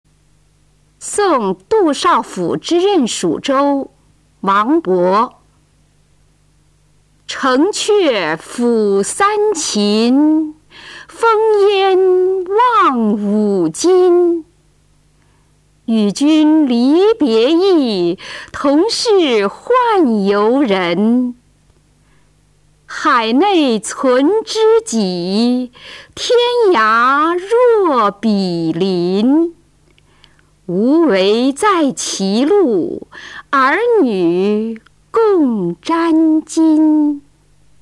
王勃《送杜少府之任蜀州》原文和译文（含赏析、朗读）　/ 王勃